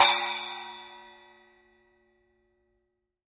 10" Splash Becken
10_splash_edge.mp3